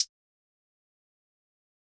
OZ - HH 5.wav